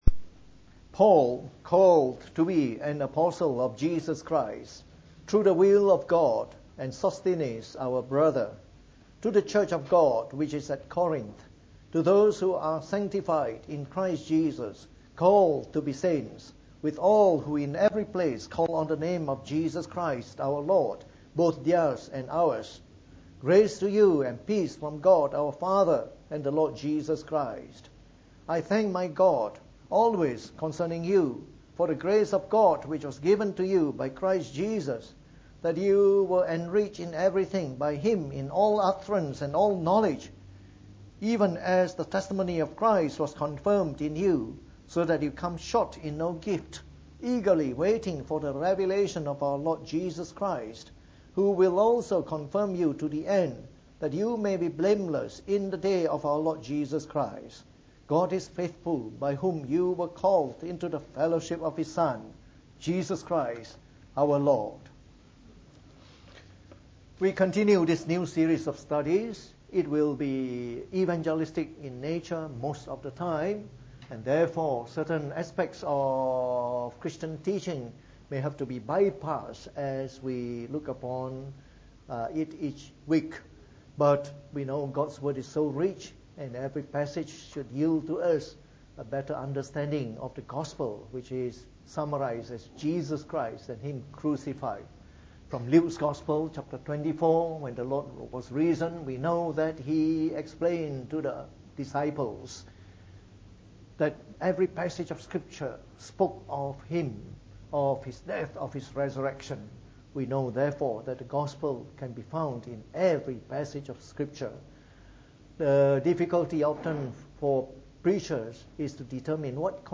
From our new series on 1 Corinthians delivered in the Evening Service.